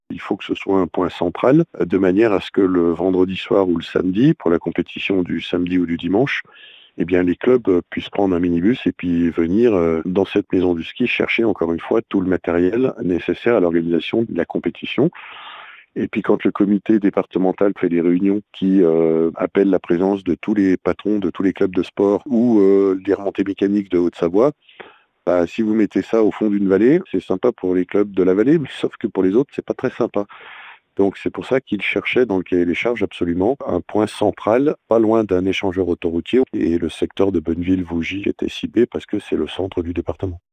Un choix qui peut faire rire, mais qui a été réfléchi, comme l'explique Martial Saddier.